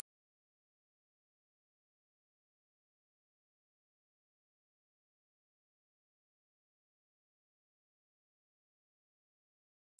Ear Ringing (After explosion)
描述：Created using Logic Pro. Ear Ringing sound effect similar to that when there's an explosion.
标签： simulated insanity ringing ear
声道立体声